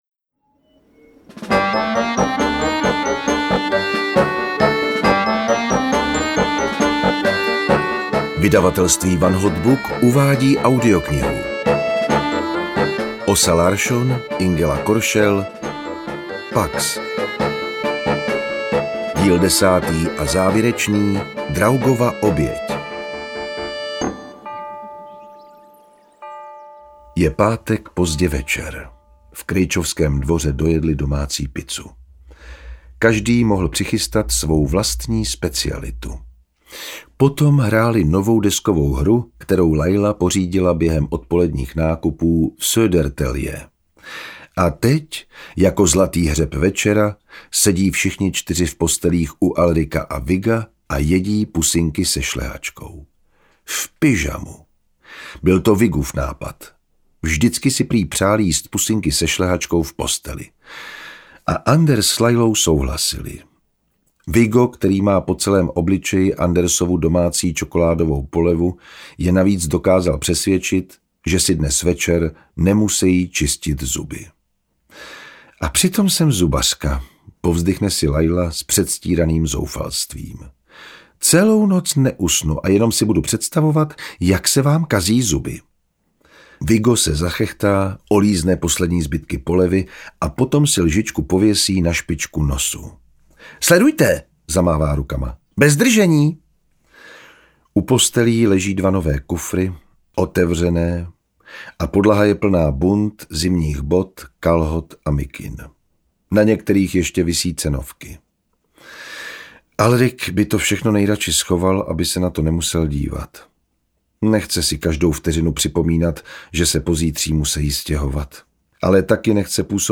PAX 10: Draugova oběť audiokniha
Ukázka z knihy
• InterpretJan Vondráček